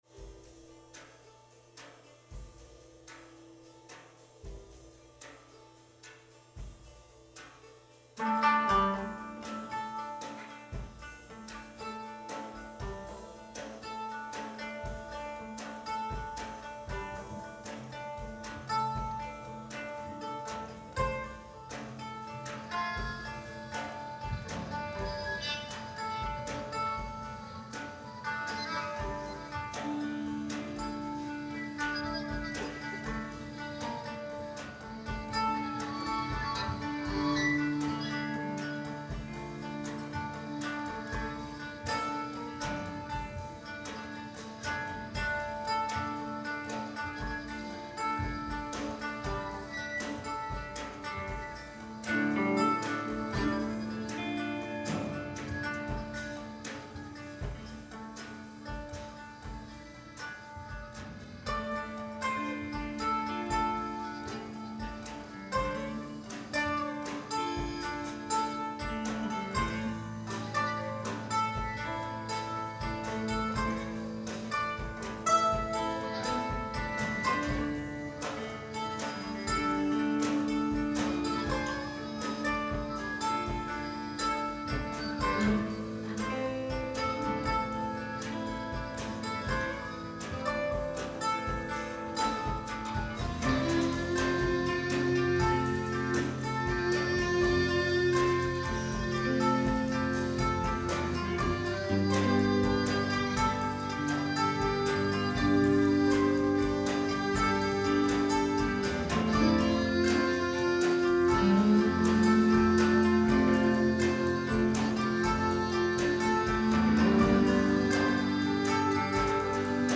live at the Museum of Fine Arts Boston